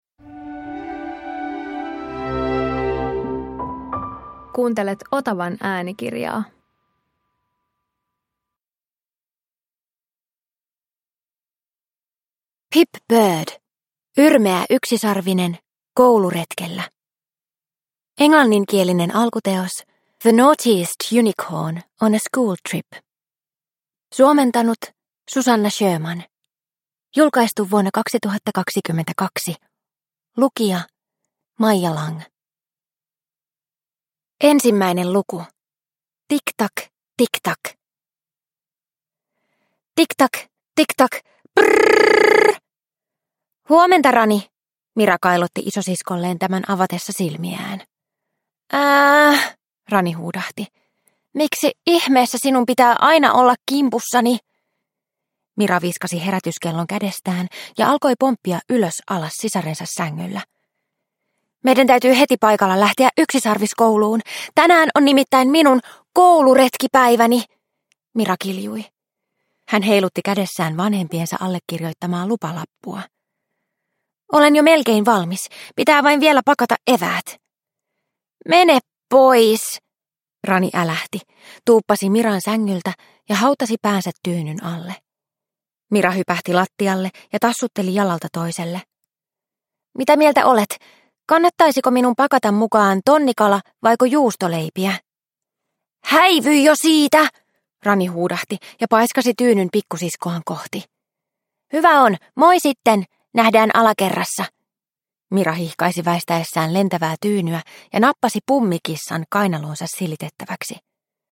Yrmeä yksisarvinen kouluretkellä – Ljudbok